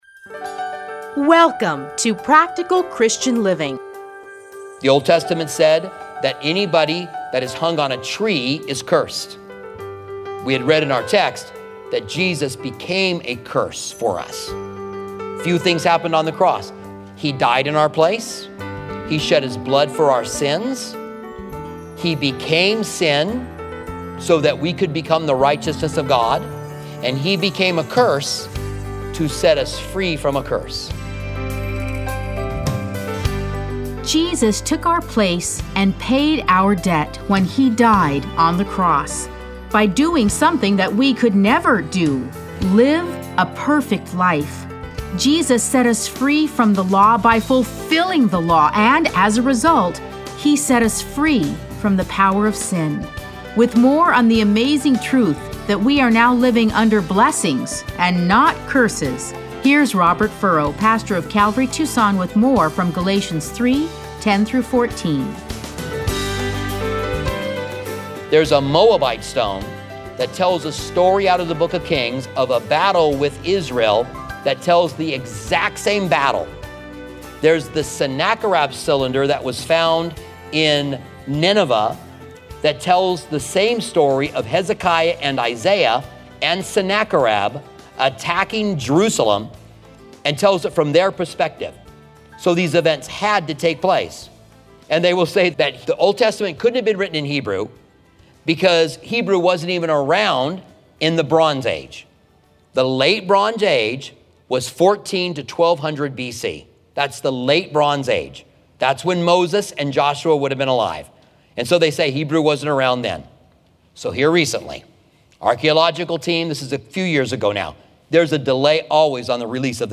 Listen to a teaching from Galatians 3:10-14.